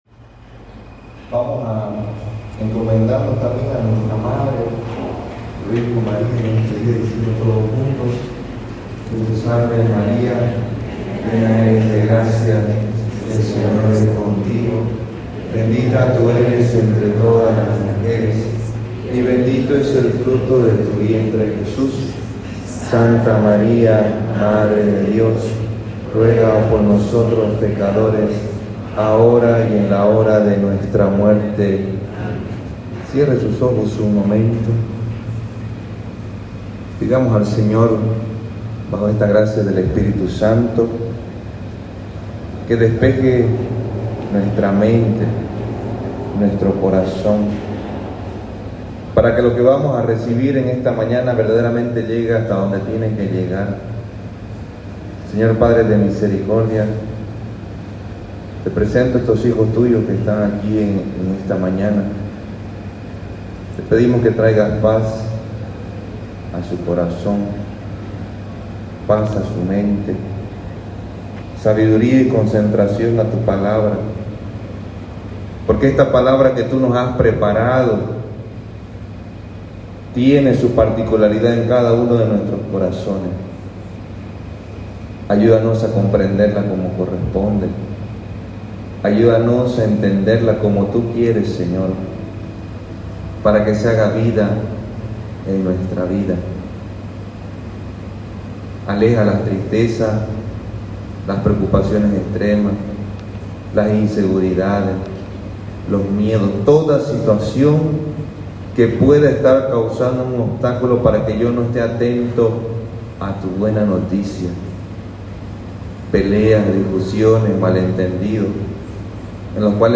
Retiro Semana Santa 2023 - De tal Manera Amó Dios al Mundo